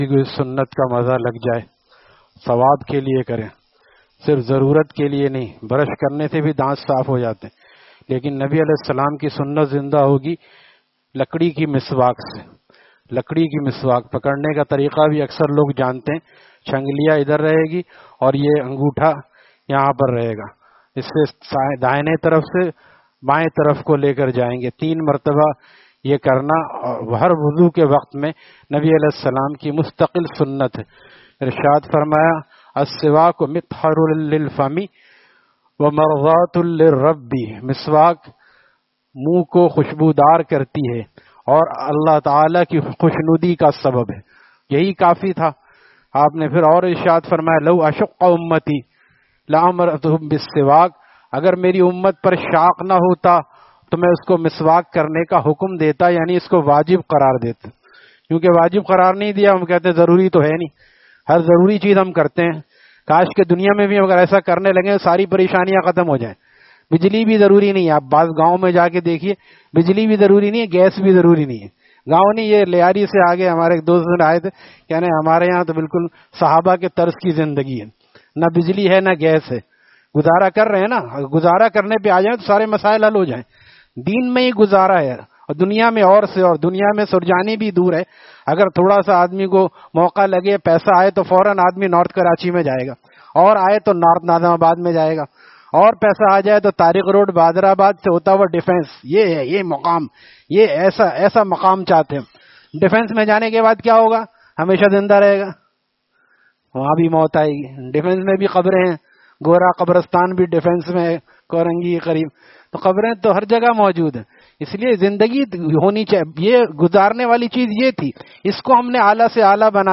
Taleem After Fajar at Jamia Masjid Gulzar e Muhammadi, Khanqah Gulzar e Akhter, Sec 4D, Surjani Town